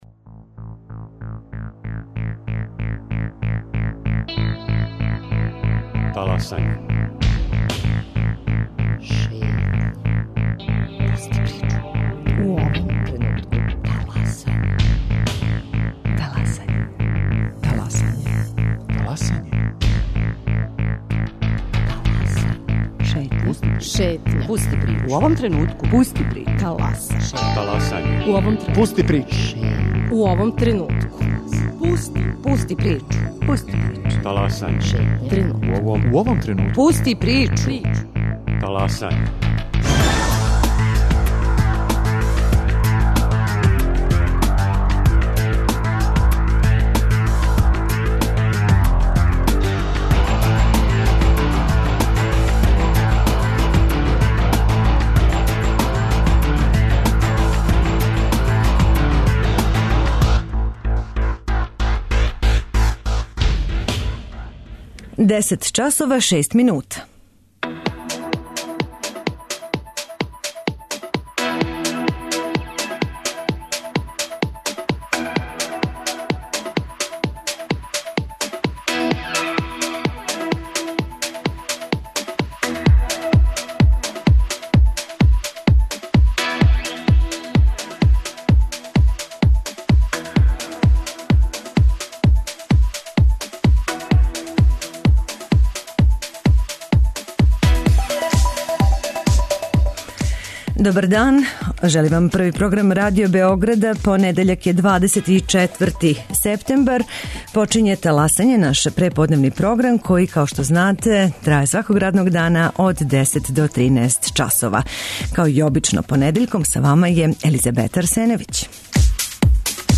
У студију ће бити представнице неформалне омладинске групе Респиратор која је пројектом "Блејакт: Калуђерица" покушала да културно пробуди омладину овог - и даље нелегализованог - београдског насеља, као и представници такође неформалне омладинске групе Belgrade Art Teasers која управо реализује "Art Base Festival", представљајући радове неафирмисаних младих уметника и уметница.